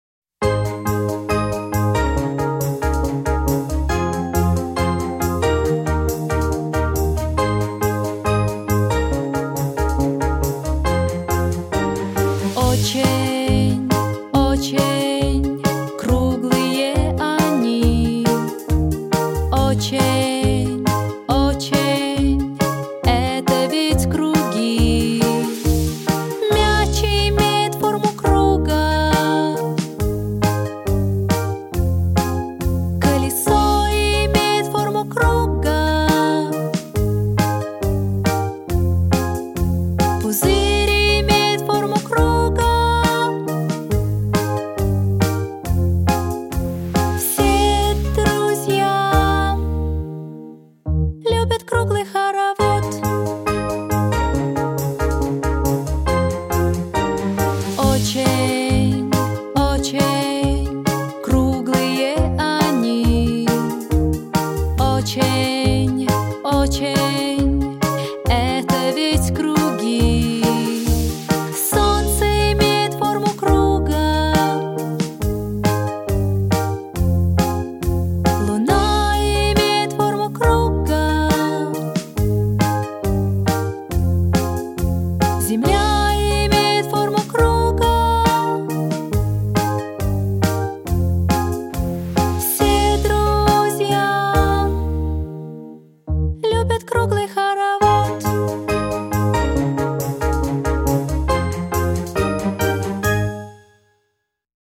• Жанр: Детские песни
малышковые